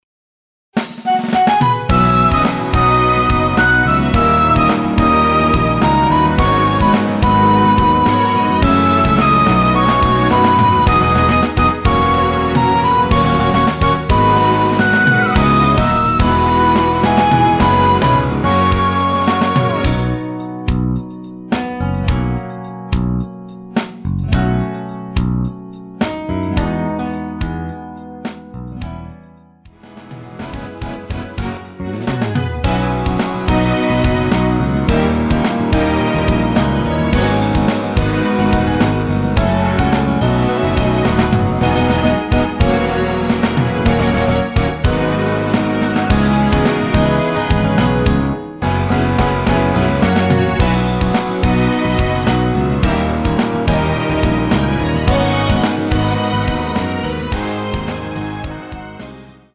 내린 MR입니다.
원곡의 보컬 목소리를 MR에 약하게 넣어서 제작한 MR이며